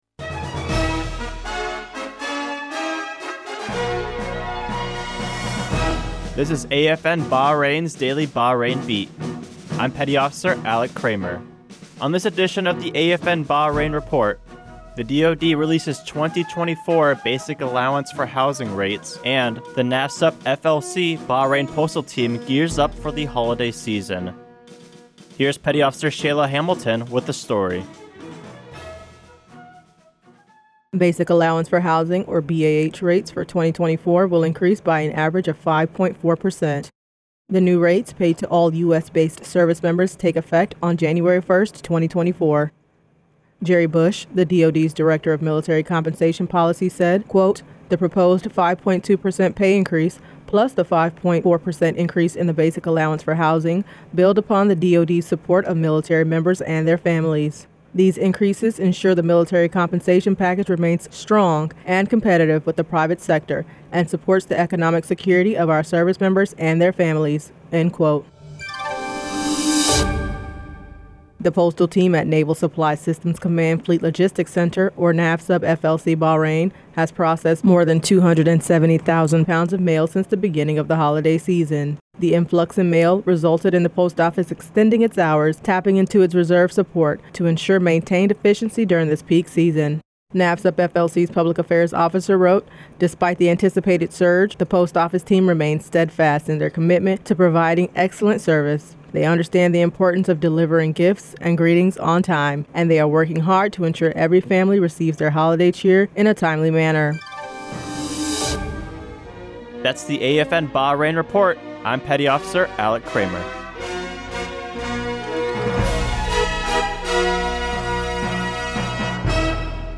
Two-minute newscast covering basic allowance for housing increases and NAVSUP FLC Bahrain's holiday postage milestone.